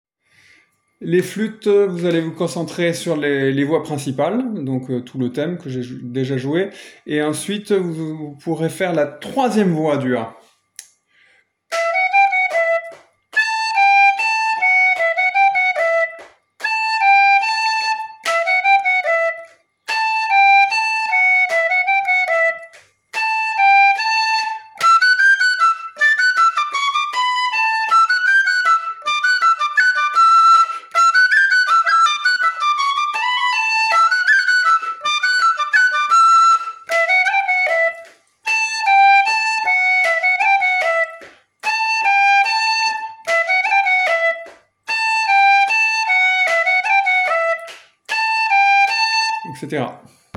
les-boyaux-flûtes-.mp3